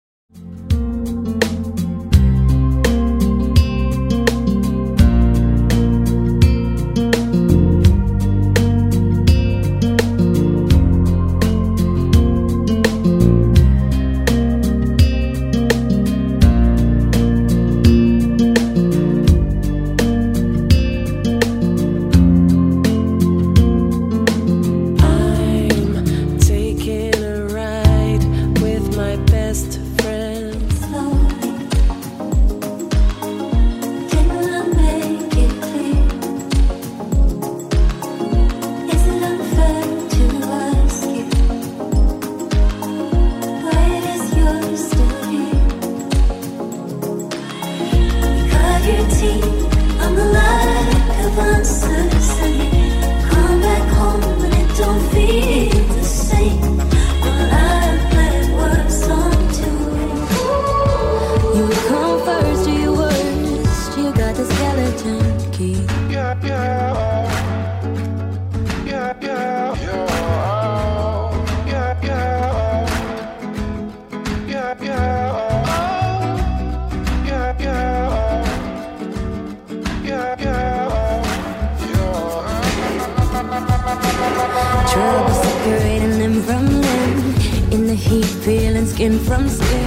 Lounge Music
Chilled Acid Jazz & Trip Hop